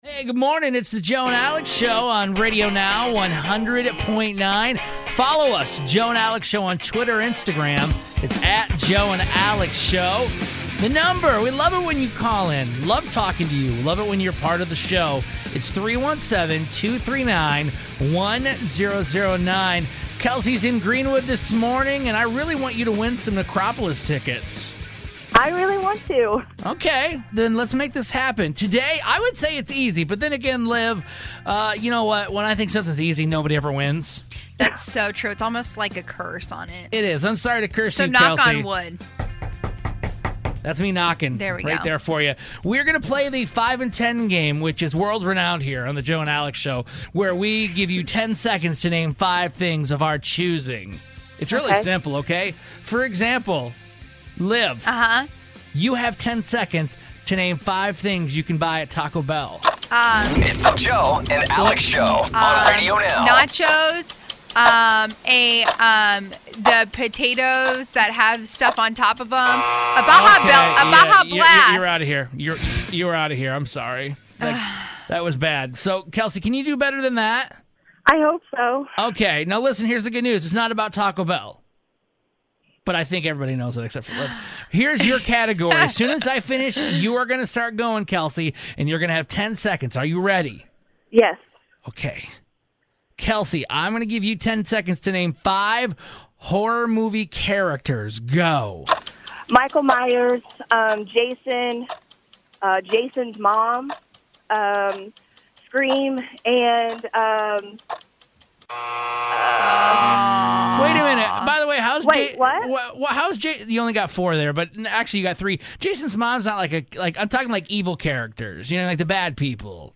We have callers list off 5 scary movie characters in 10 seconds to win Necropolis tickets.